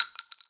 casingfall2.ogg